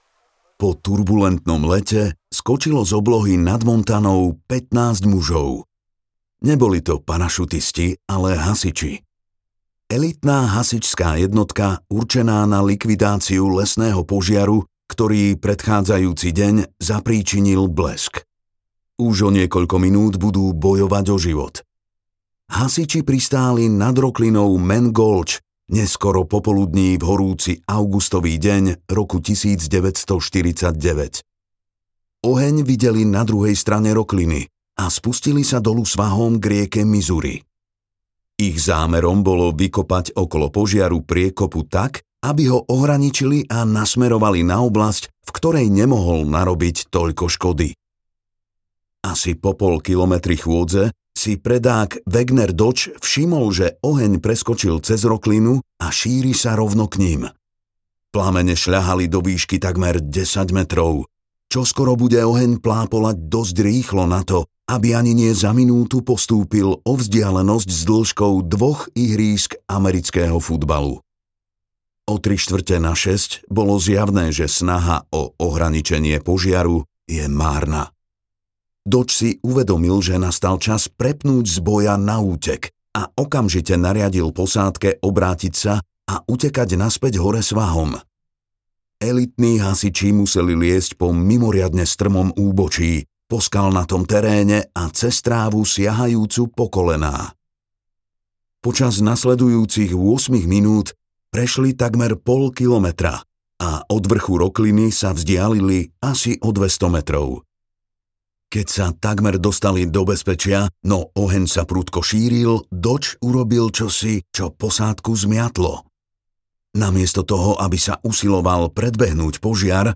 audiokniha
Čte: Martin Kaprálik